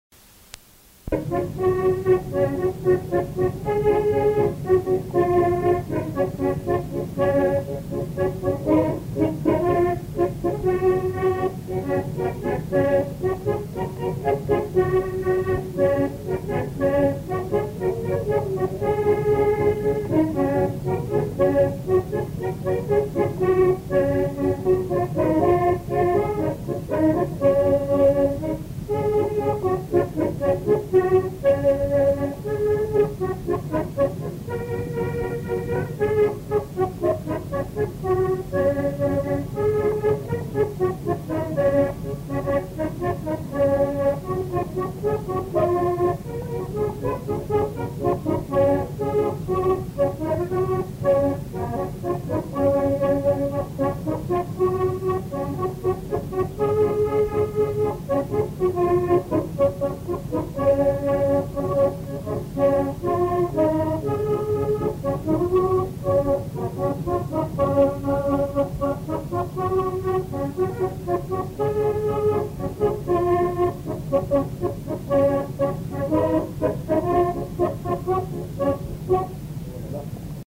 Aire culturelle : Haut-Agenais
Lieu : Monclar d'Agenais
Genre : morceau instrumental
Instrument de musique : accordéon diatonique
Danse : marche (danse)
Ecouter-voir : archives sonores en ligne